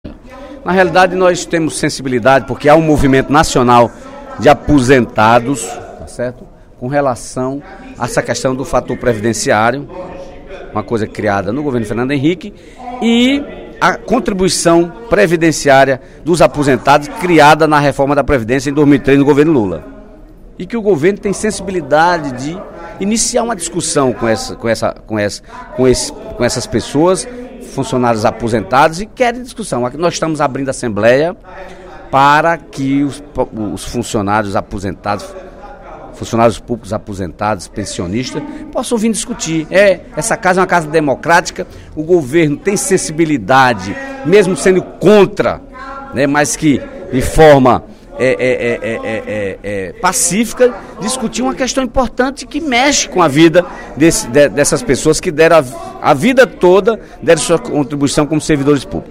O deputado Dedé Teixeira (PT) fez pronunciamento no primeiro expediente da sessão plenária da Assembleia Legislativa desta sexta-feira (27/09), para comunicar a realização da audiência pública proposta pelo movimento de servidores públicos aposentados e pensionistas para discutir a PEC 555, que extingue a contribuição previdenciária dos servidores inativos.